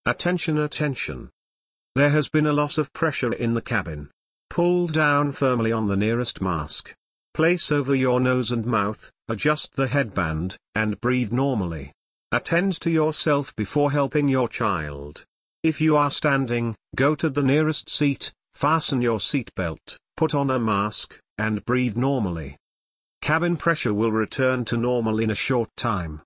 depressurization.wav